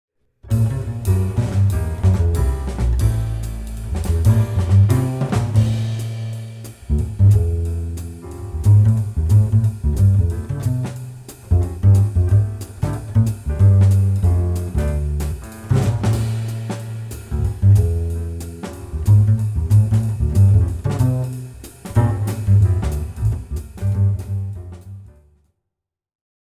groep5_les1-4-2_strijkinstrumenten2.mp3